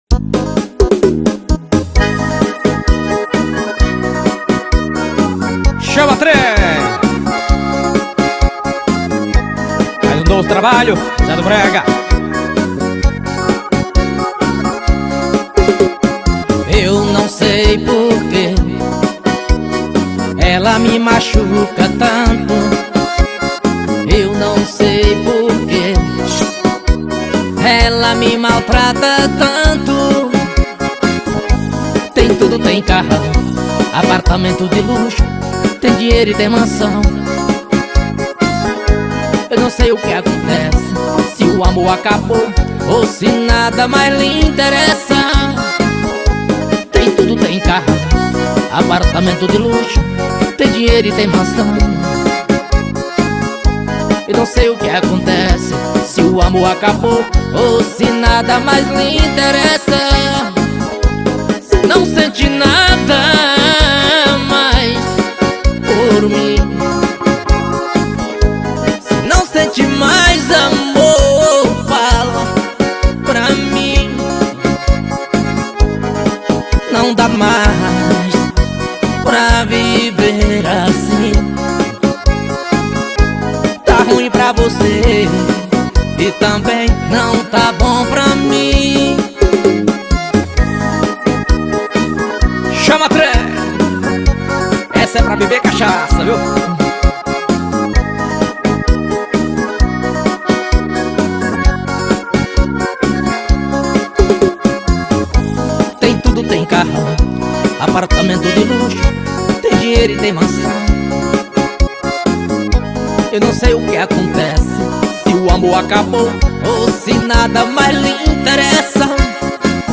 2025-01-28 15:23:15 Gênero: Forró Views